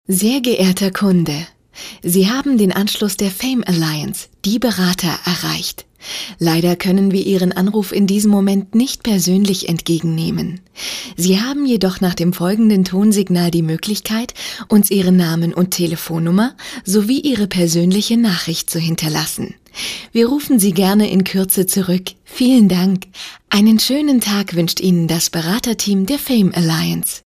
- Extrem wandelbare Stimme!
Außergewöhnlich vielfältige Sprecherin.
Sprechprobe: eLearning (Muttersprache):